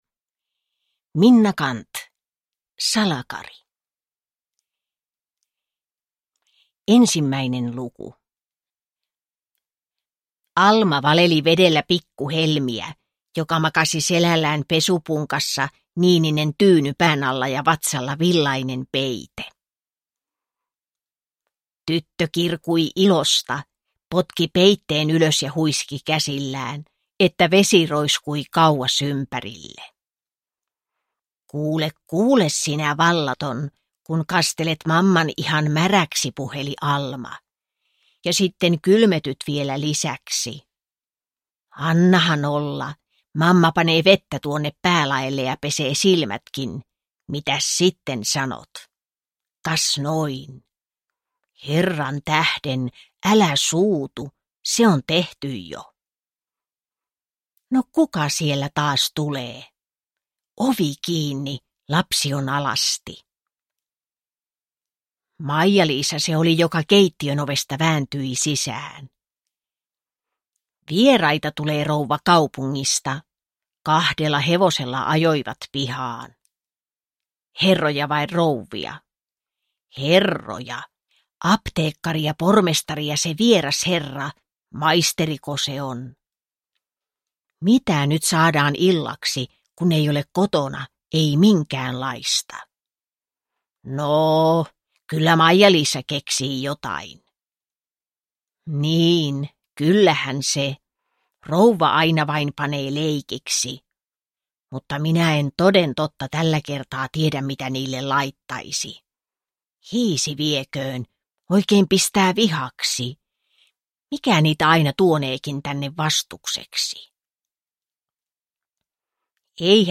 Salakari – Ljudbok – Laddas ner